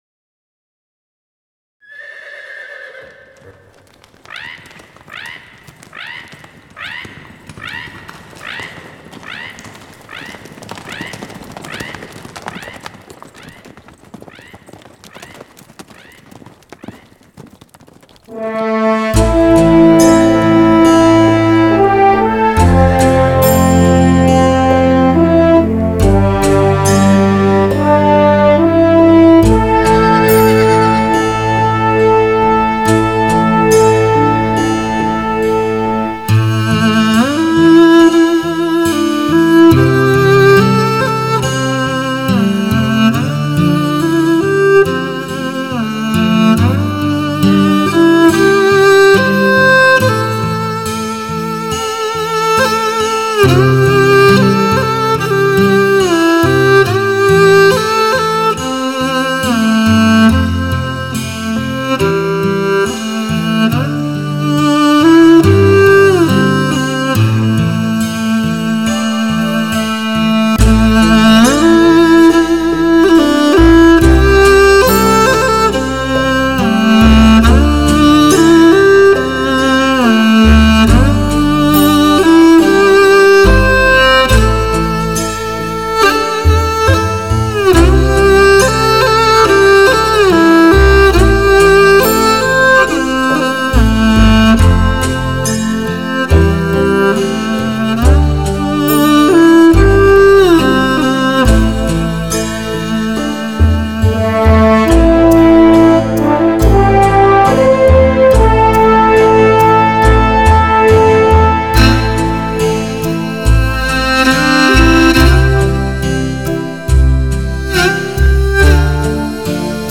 2周前 纯音乐 8